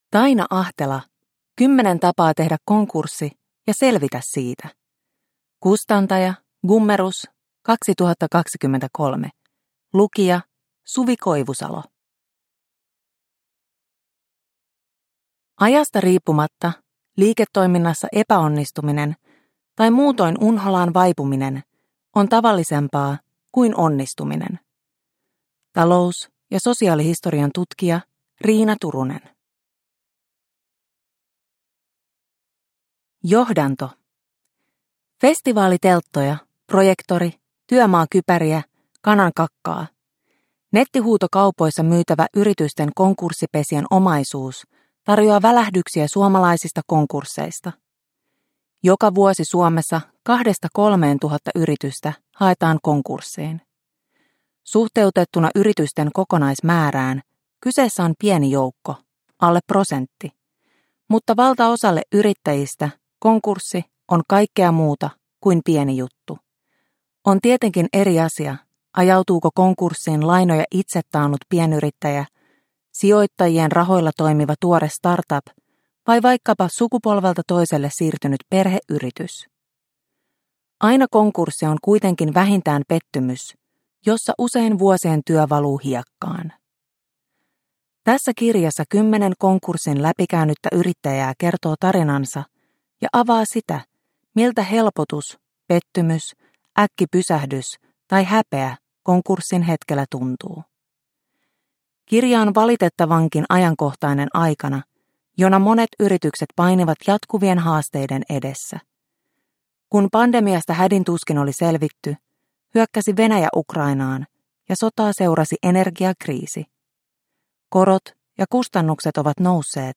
10 tapaa tehdä konkurssi – Ljudbok – Laddas ner